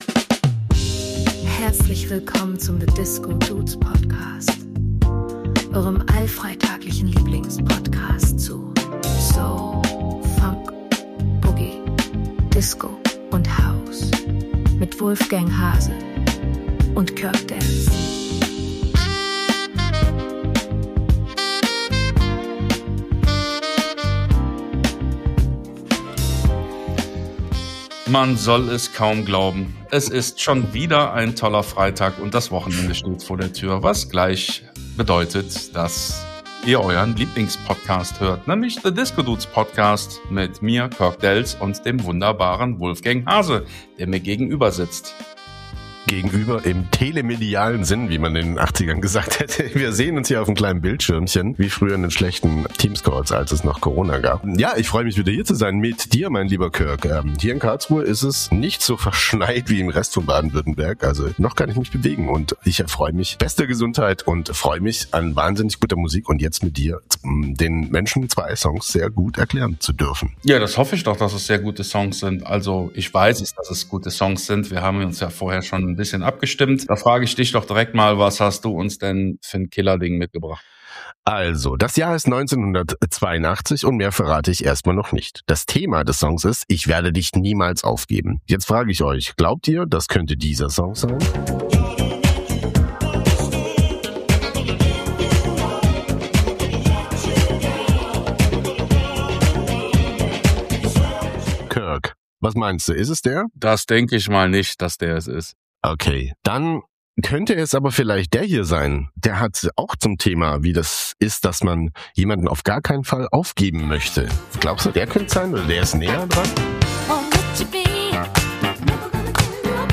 🪩 In Folge 70 trifft Boogie-Feuer aus ’82 auf Philly-Soul im Samtanzug – perfekt für deinen Friday-Glow.